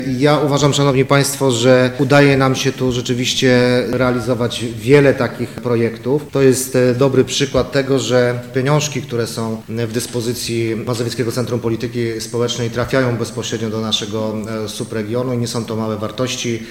-mówił wicemarszałek województwa mazowieckiego Rafał Rajkowski.